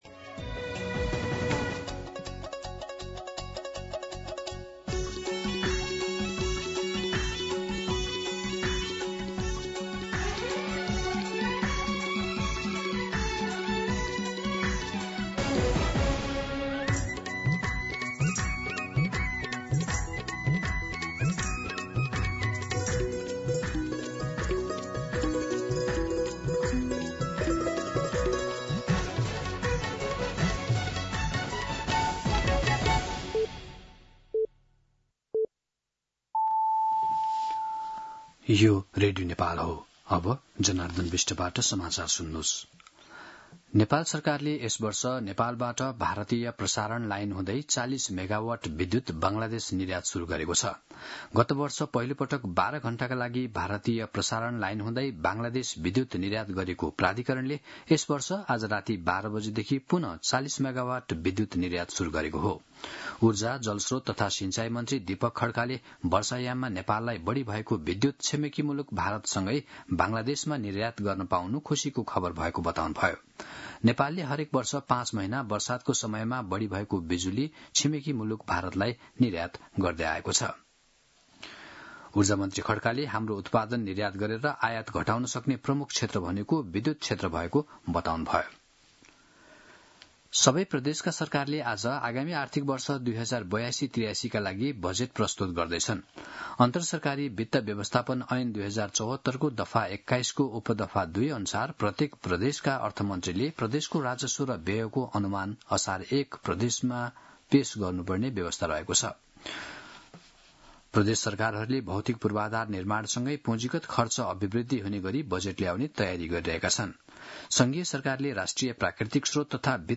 मध्यान्ह १२ बजेको नेपाली समाचार : १ असार , २०८२